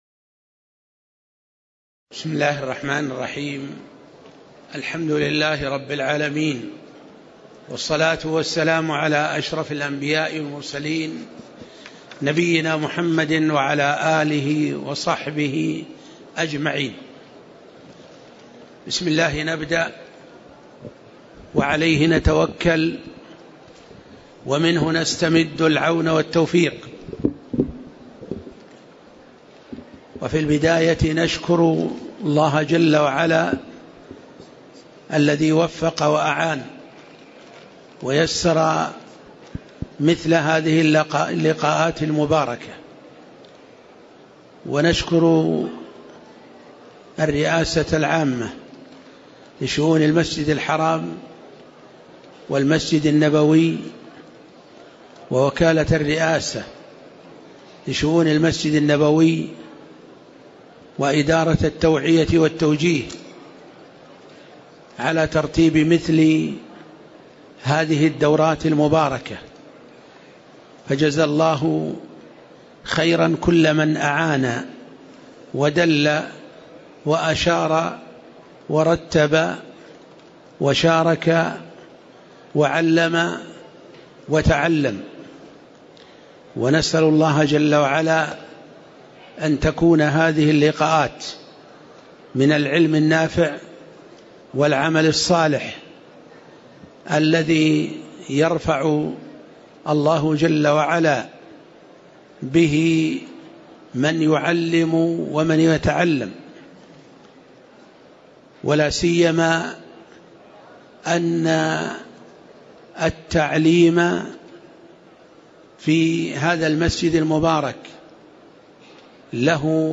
تاريخ النشر ١٤ شوال ١٤٣٨ هـ المكان: المسجد النبوي الشيخ